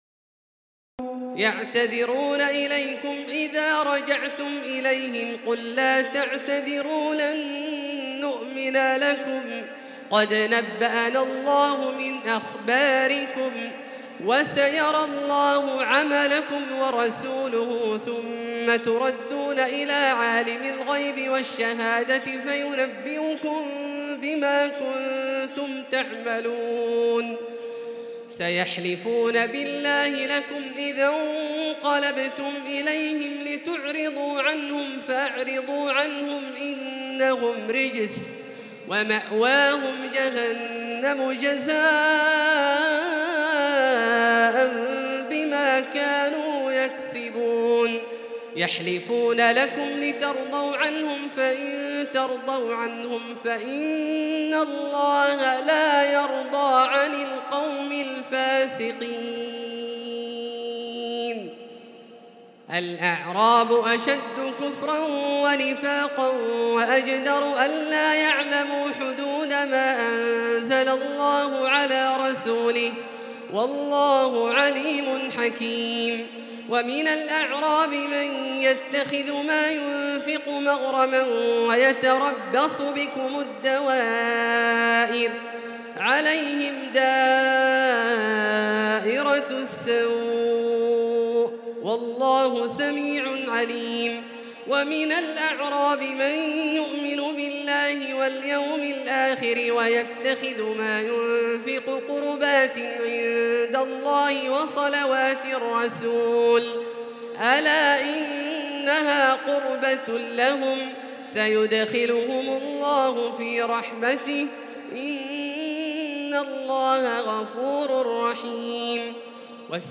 تراويح الليلة العاشرة رمضان 1438هـ من سورتي التوبة (94-129) و يونس (1-25) Taraweeh 10 st night Ramadan 1438H from Surah At-Tawba and Yunus > تراويح الحرم المكي عام 1438 🕋 > التراويح - تلاوات الحرمين